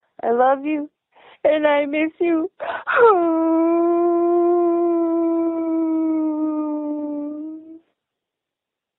Girlfriend Crying Uh Uhuuu!